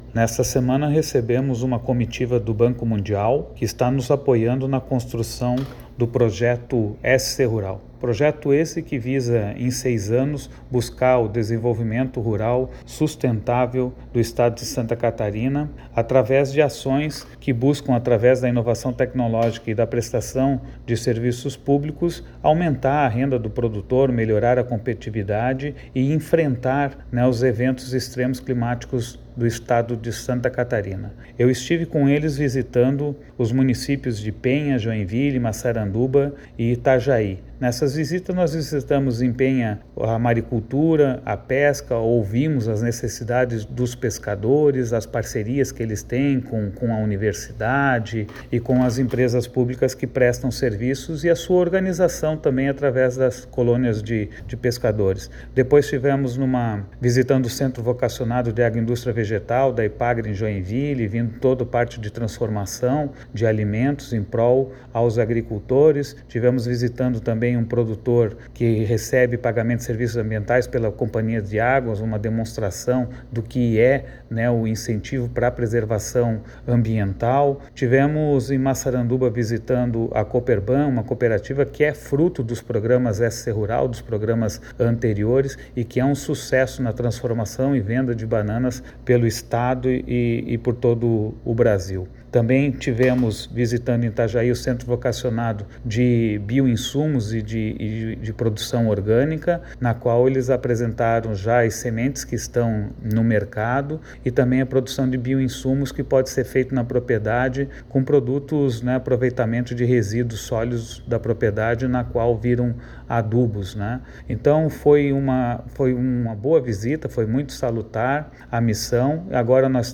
O secretário de Estado da Agricultura e Pecuária em exercício, Paulo Arruda, faz um balanço da visita e detalha a agenda cumprida em Santa Catarina:
SECOM-Sonora-Secretario-Agricultura-Exercicio-Banco-Mundial.mp3